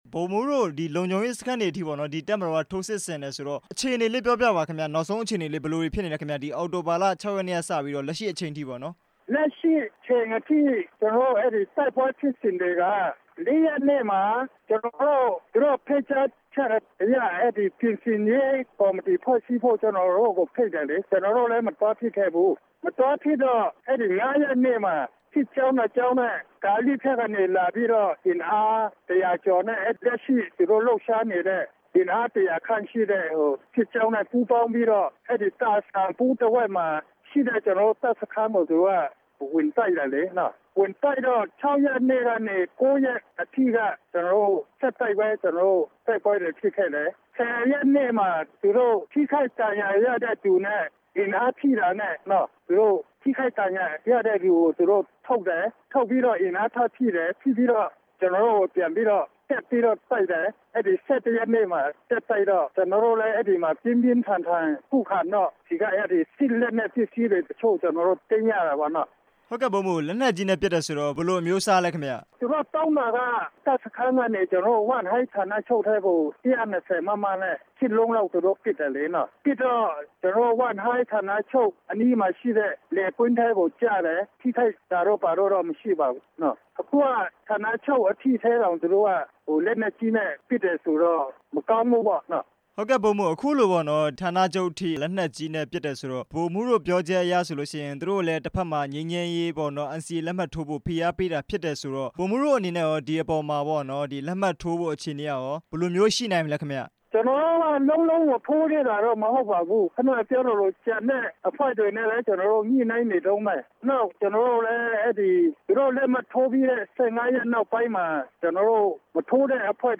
SSPP/SSA ကြေညာချက် ထုတ်ပြန်တဲ့အကြောင်း မေးမြန်းချက်